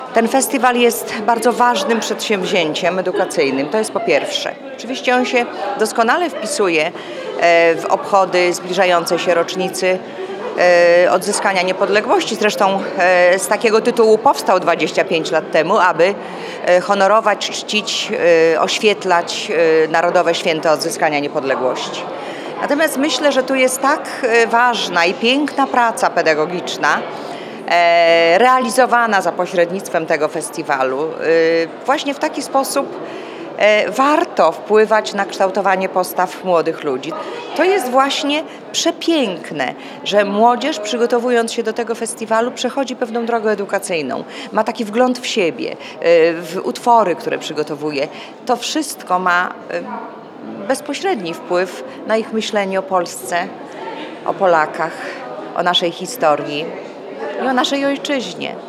– Festiwal to bardzo ważne przedsięwzięcie edukacyjne doskonale wpisujące się w obchody rocznicy odzyskania niepodległości – mówi Jadwiga Mariola Szczypiń, Podlaski Kurator Oświaty.